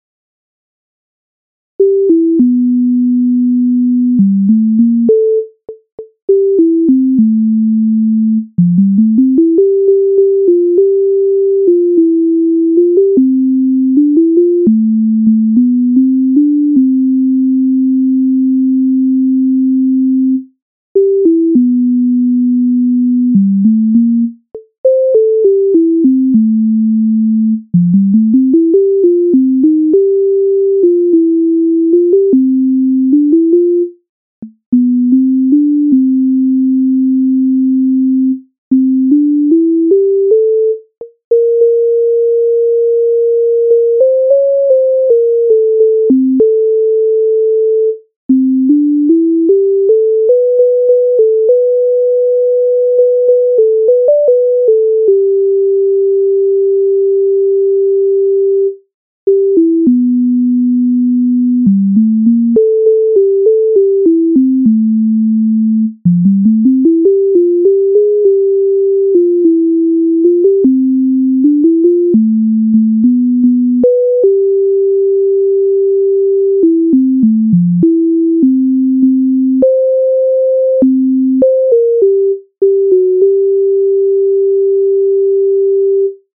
MIDI файл завантажено в тональності C-dur